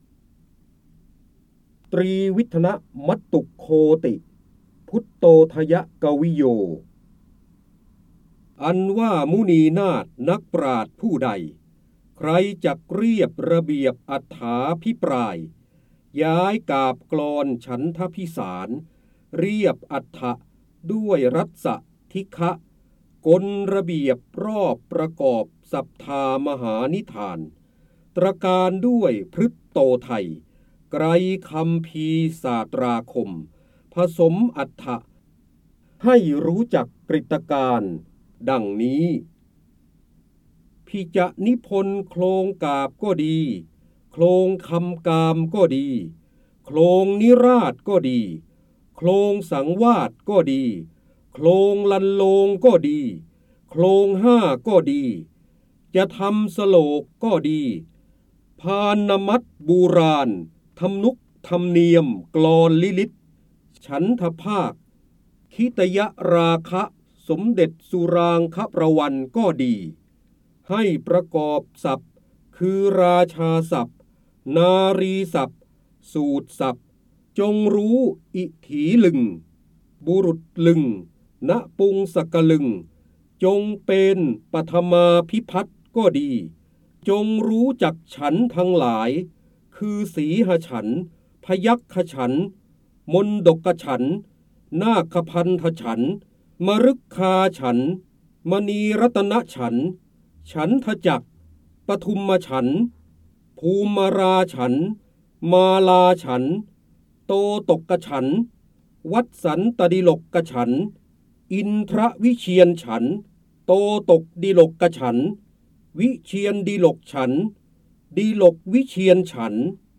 เสียงบรรยายจากหนังสือ จินดามณี (พระโหราธิบดี) ตรีวิธนสมตุคโคติ พุตุโตทยกวิโย
คำสำคัญ : ร้อยกรอง, การอ่านออกเสียง, จินดามณี, พระเจ้าบรมโกศ, ร้อยแก้ว, พระโหราธิบดี